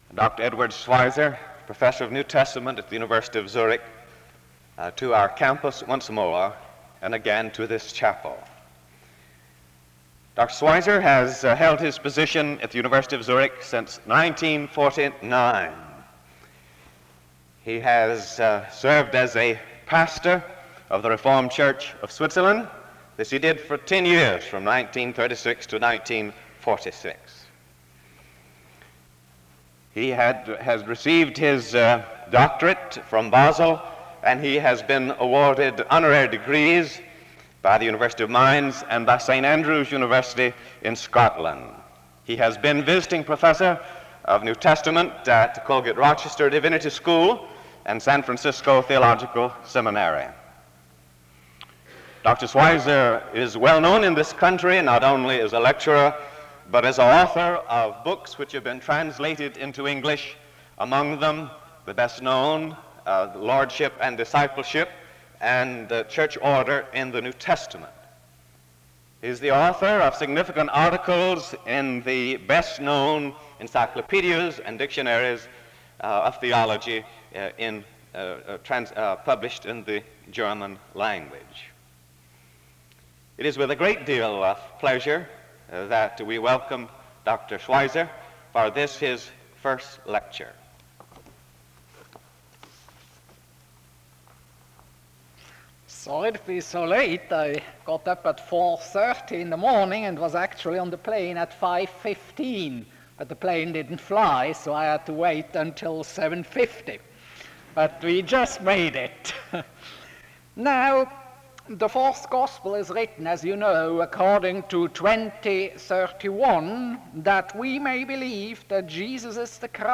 The service opens with an introduction to the speaker from 0:00-1:31. Dr. Schweizer speaks from 1:36-45:12.
Schweizer preaches on the incarnation of Christ. A closing prayer is offered from 45:20-46:06. This is part 1 of a 2 part lecture series.